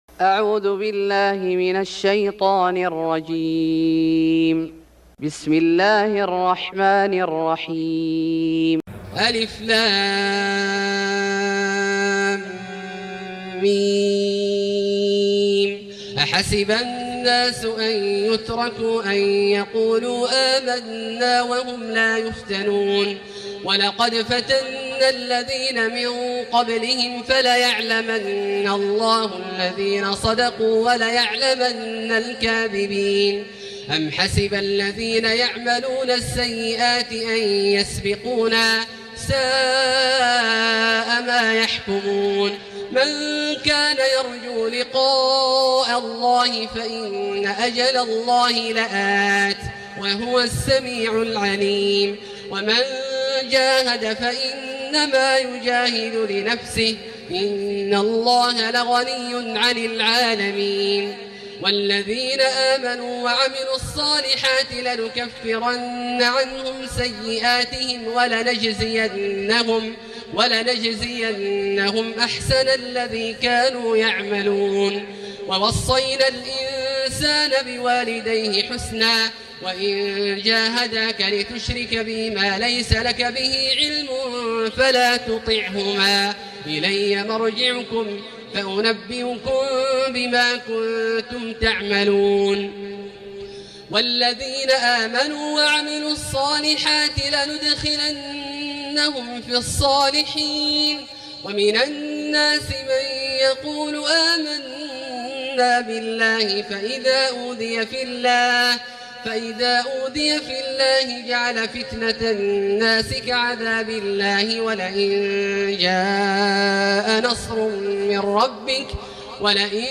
سورة العنكبوت Surat Al-Ankbut > مصحف الشيخ عبدالله الجهني من الحرم المكي > المصحف - تلاوات الحرمين